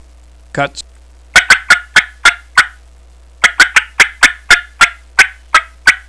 Listen to 6 seconds of cutts
yythreetwocutts6.wav